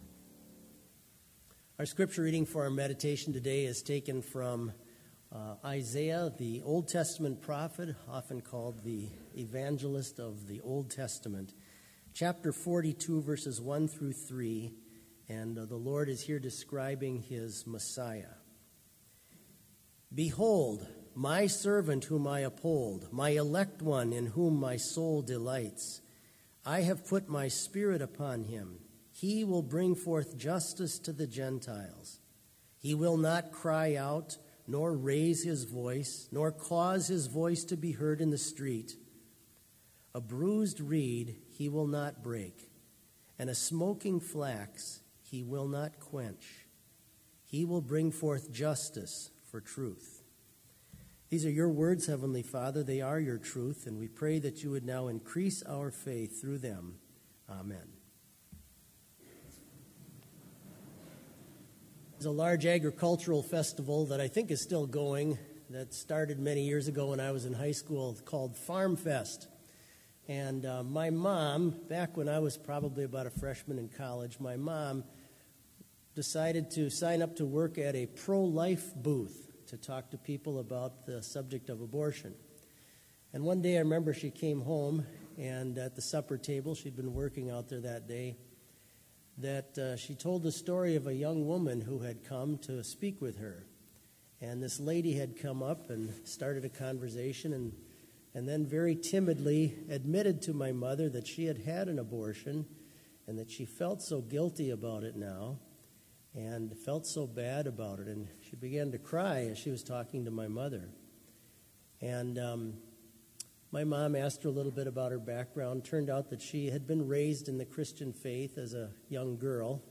Complete service audio for Chapel - February 8, 2019
Watch Listen Complete Service Audio file: Complete Service Sermon Only Audio file: Sermon Only Order of Service Prelude Hymn 370 - The King of Love, My Shepherd is View vv. 1-4 Reading: Isaiah 42:1-3 View “Behold!